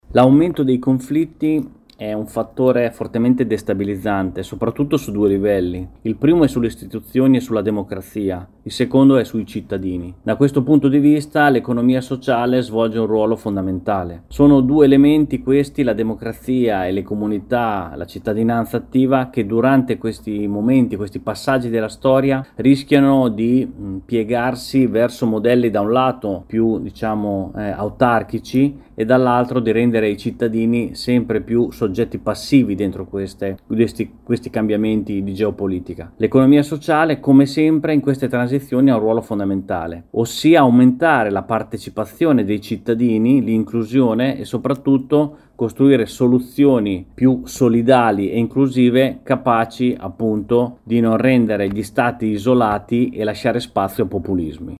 Bentornati all’ascolto del Grs Week.